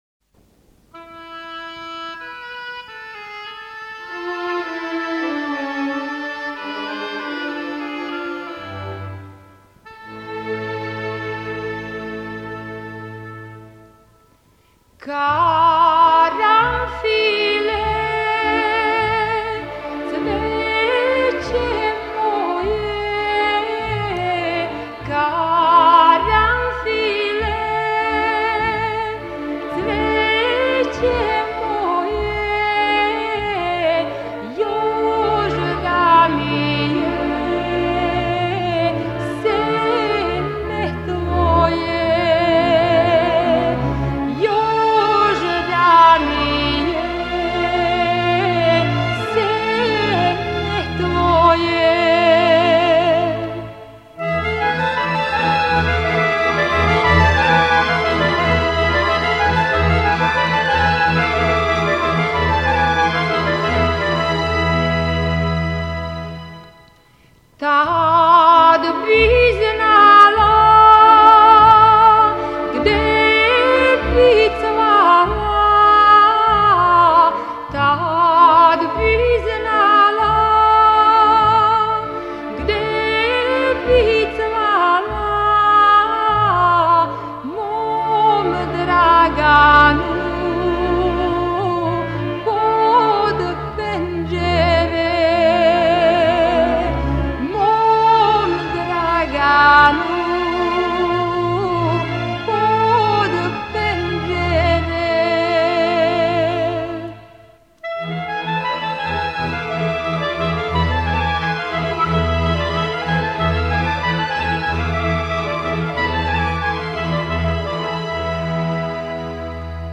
Напомена: Љубавна (девојачка) песма.